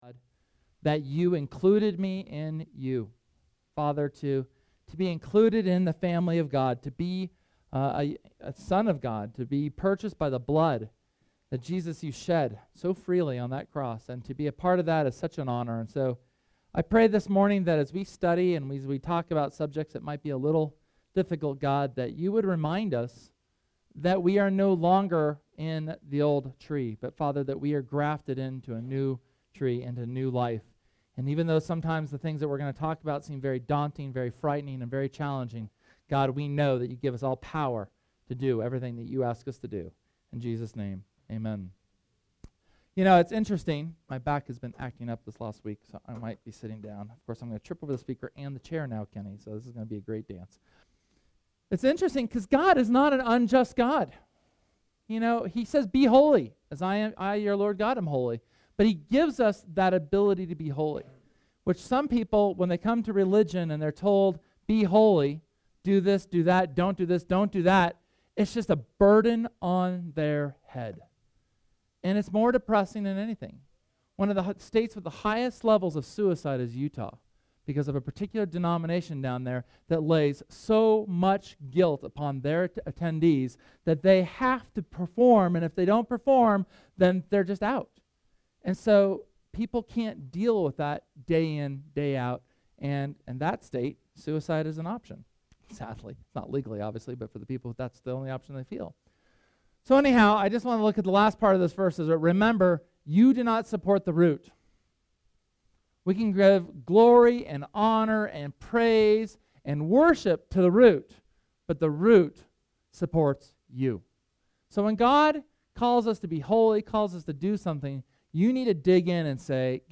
SERMON: Reconciled Relationships (Col. #7) – Church of the Resurrection